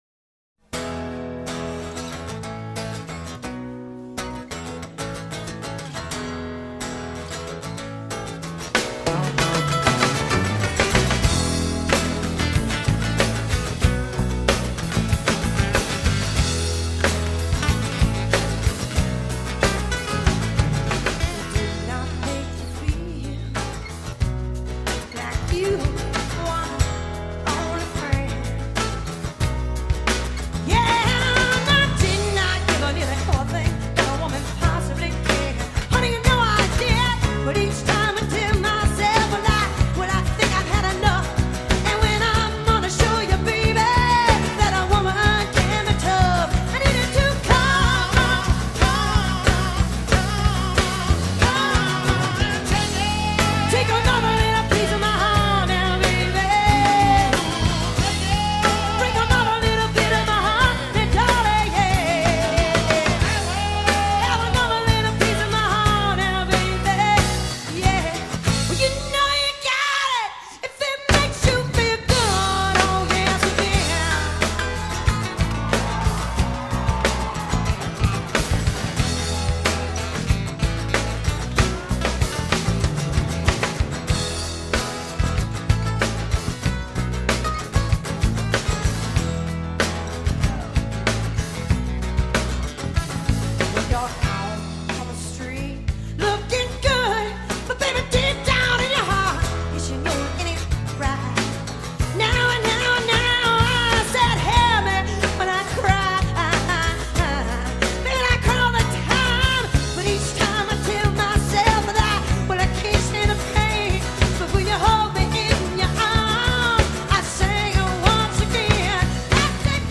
performed and recorded live